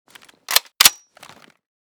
g3_unjam.ogg